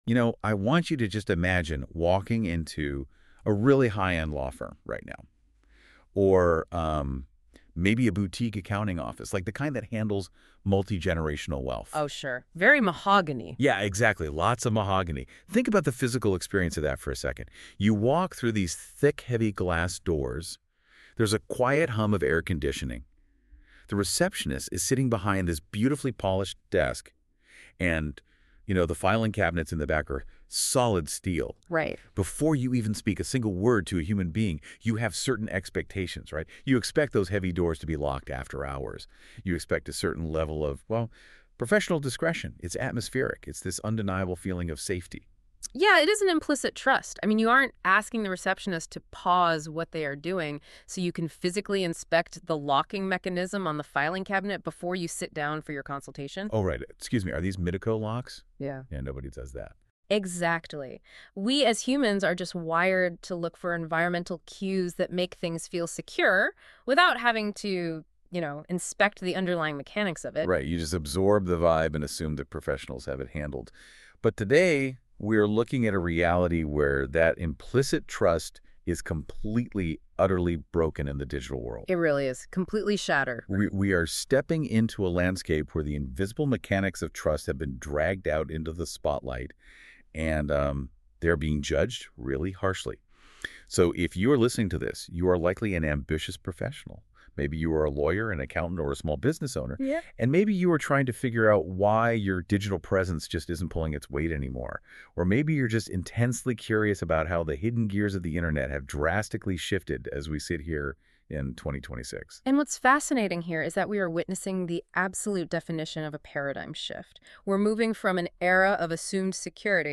Want a more conversational take on everything covered in this post? This NotebookLM-generated deep dive walks through why website security is now a public ranking signal, what the VCDPA means for Virginia businesses, and how visible trust signals affect both SEO and client confidence — in an accessible audio format you can follow while you work.
Audio generated via Google NotebookLM — sourced from the research and references underlying this post.